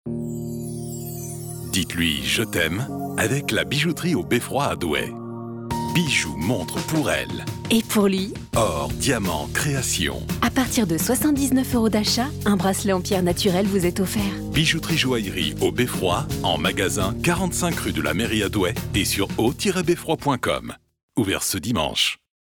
Découvrez notre spot radio sur Chérie FM à l'occasion de la Saint-Valentin